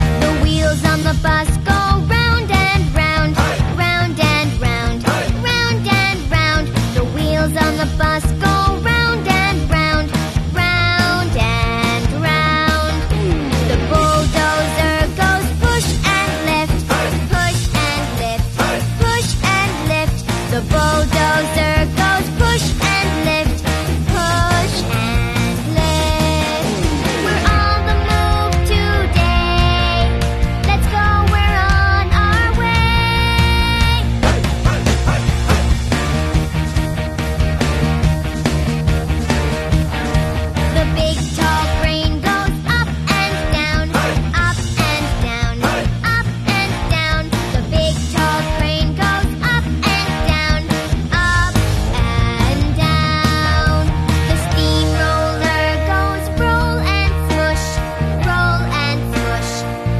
Less screentime for kids!!! E-BOOK Pronunciation Speaking Learning Book Kid Voice Learning Language Alphabet Electric with a Smart Logic Pen.